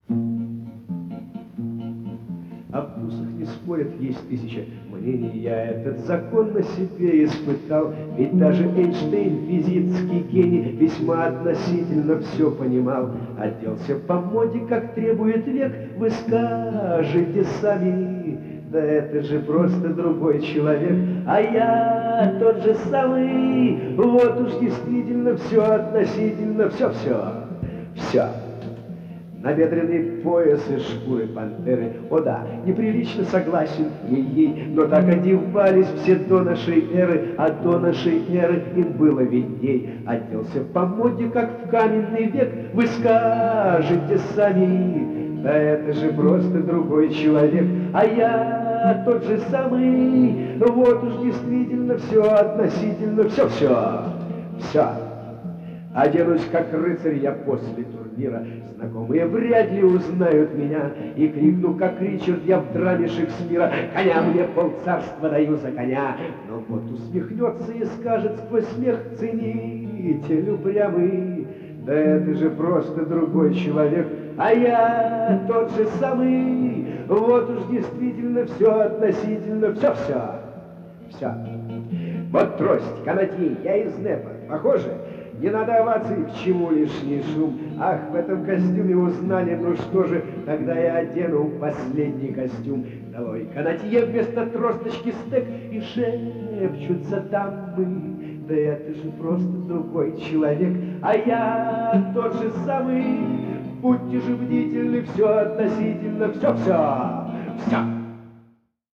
Скажу сразу - в подборке качество очень различное.